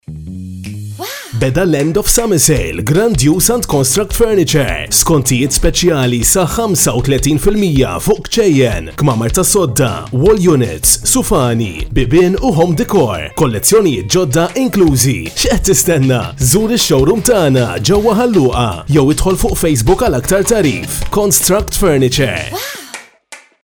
Malteser Sprecher
Cool, Zugänglich, Corporate
Kommerziell